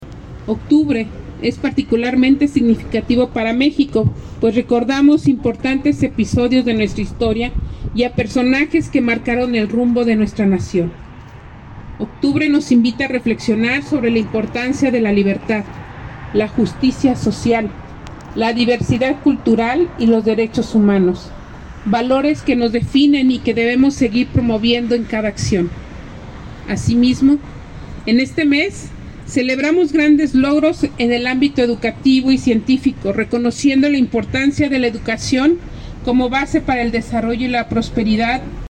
AudioBoletines
Joyce Guerrero Barajas, directora de educación